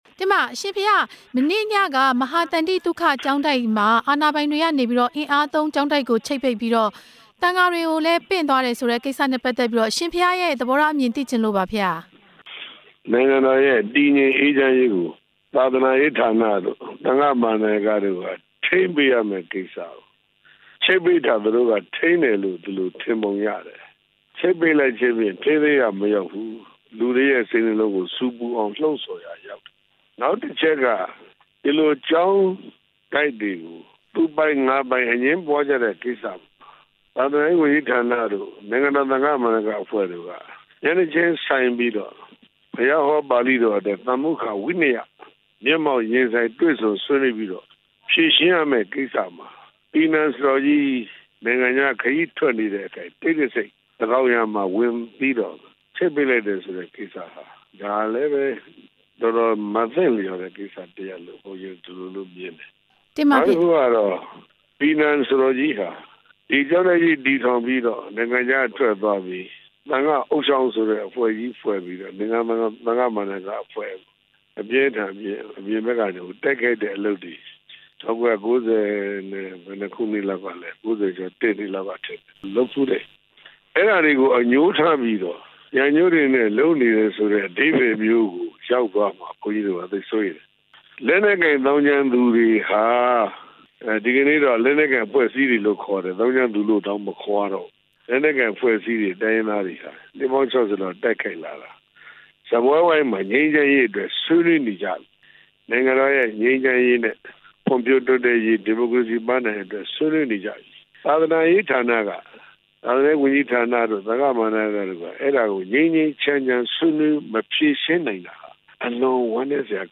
သီတဂူဆရာတော် အရှင်ဉာဏိသရ မိန့်ကြားချက်